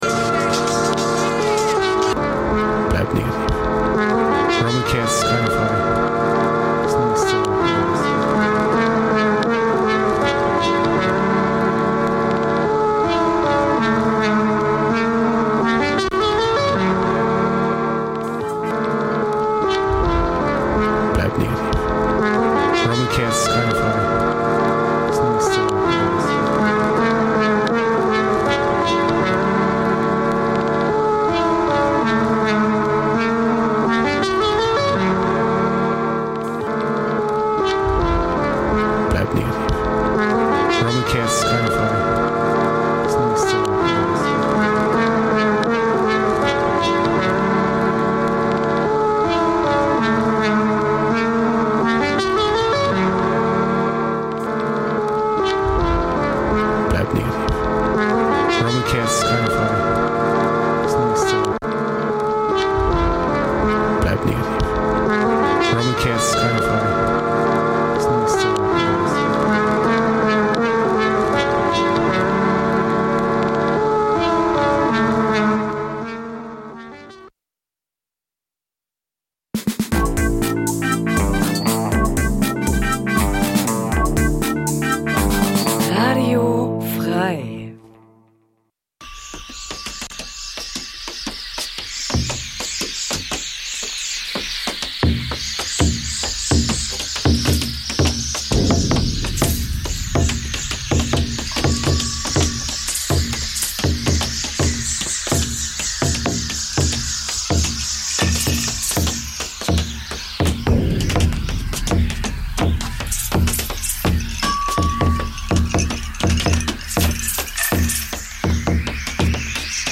F.R.E.I.-Jazz ist die vierzehnt�gige, dreist�ndige Livesendung auf Radio F.R.E.I., in der nat�rlich der Jazz im Mittelpunkt steht. Neben g�ngigen Jazzstilen wie Swing, Bebop, Hardbop, Cooljazz und Jazzrock stellen wir auch weniger repr�sentierte Spielarten des Jazz vor, die in herk�mmlichen Jazzsendungen nicht oder nur selten gespielt werden.
Nach einem Jazztitel kann schon mal ein Walzer oder Punk erklingen, jede erdenkliche Freiheit ist erlaubt.